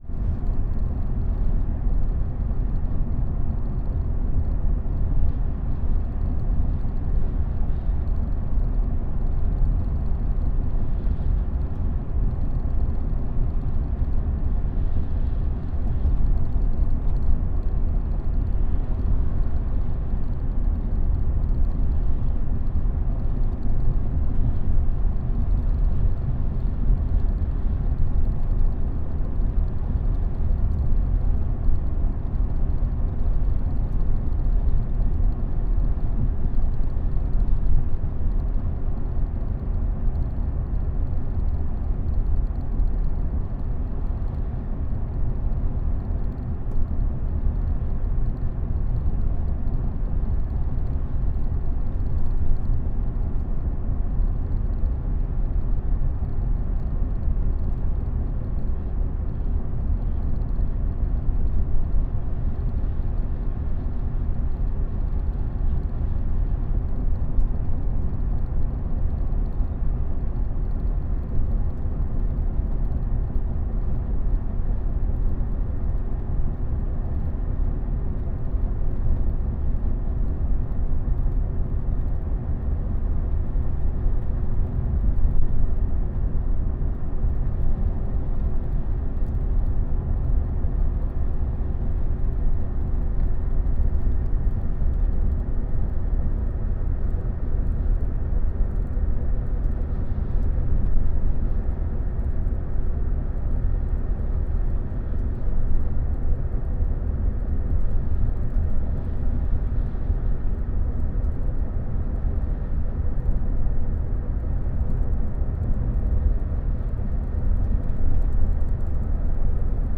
This is a compilation from the ongoing project ‘Situations and Circumstances’ as a series of field recordings that are made public in this release intending to be available for free download and potential reuse in new works of other artists.